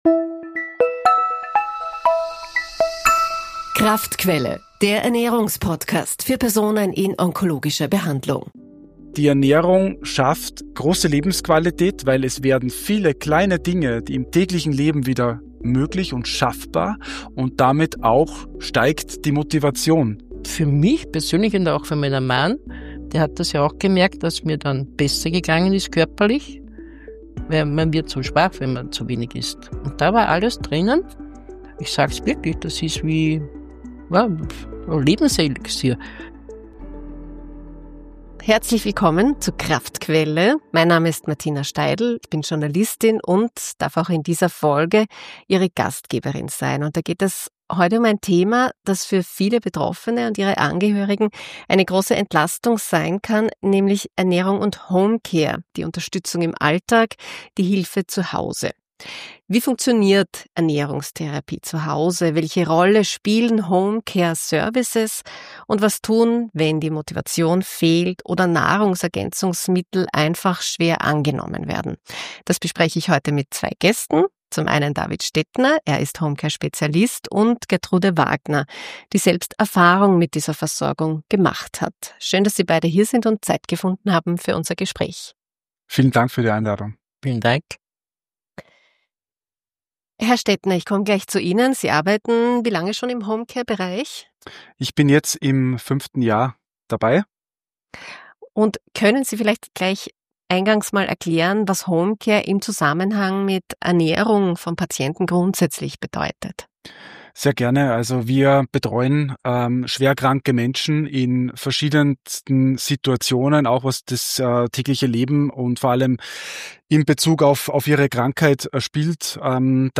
Beschreibung vor 6 Monaten In dieser Folge geht es um ein Thema, das den Alltag von Krebspatient:innen spürbar erleichtern kann: Ernährung und Homecare. Moderatorin